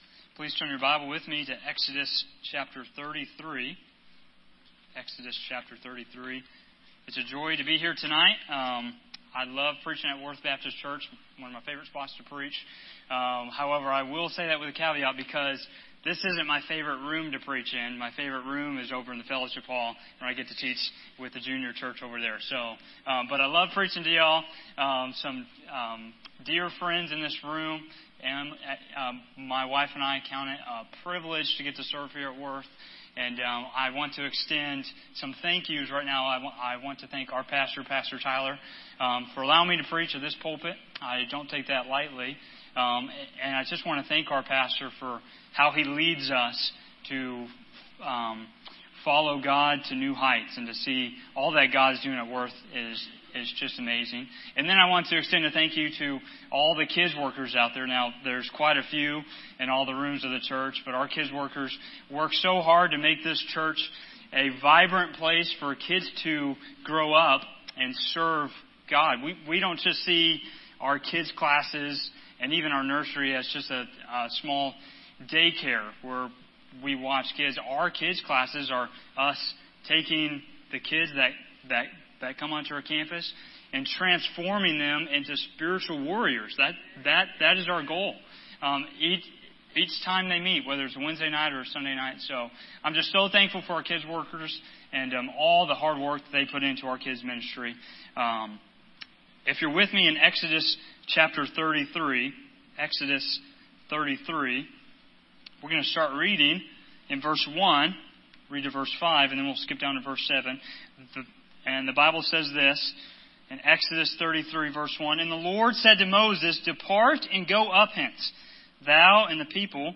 Sermons from 2024 - Media of Worth Baptist Church